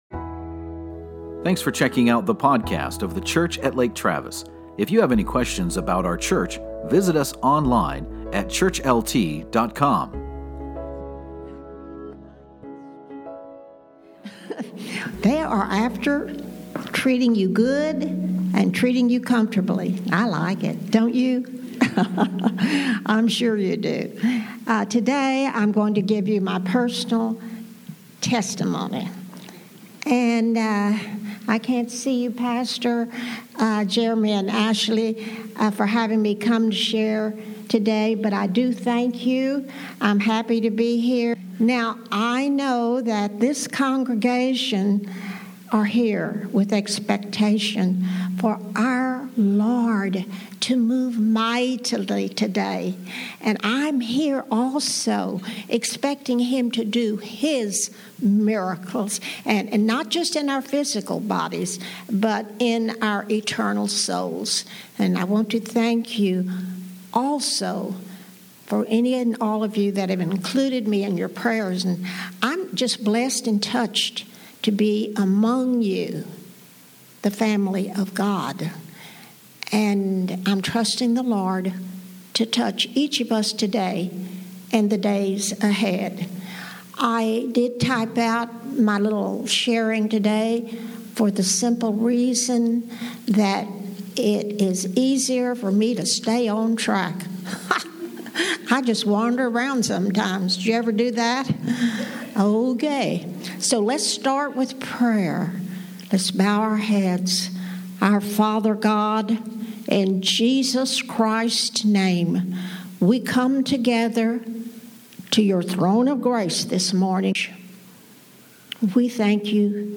Healing part 2 - Hope Rock Church